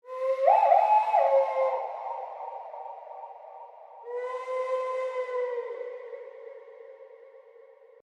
Jungle Owl
yt_S0N3K9ffJG0_jungle_owl.mp3